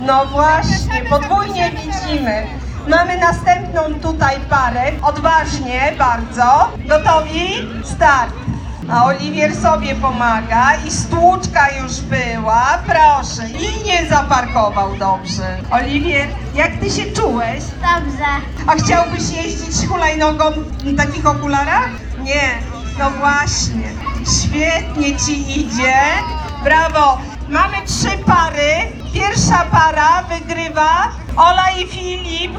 Suwałki IV Runda Pucharu Toru i Piknik Motoryzacyjny na torze przy ul. Sikorskiego w Suwałkach.